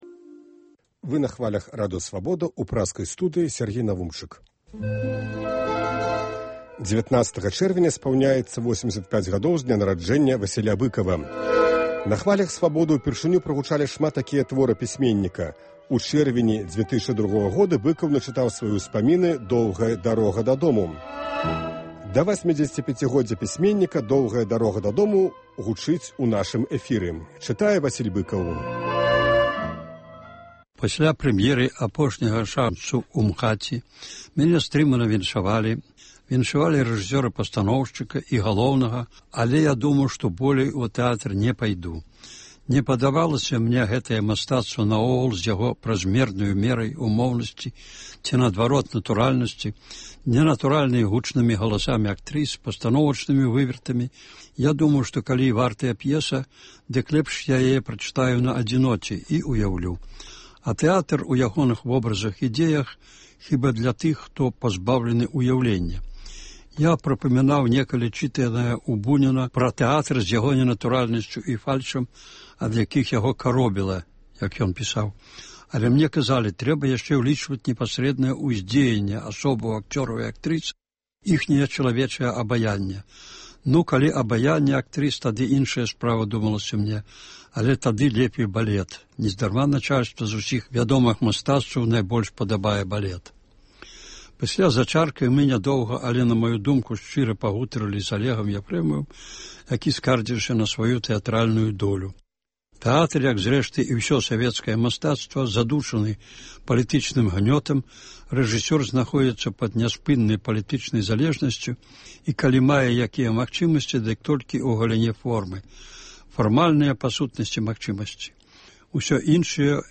З архіву "Свабоды": Васіль Быкаў чытае "Доўгую дарогу дадому"